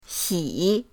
xi3.mp3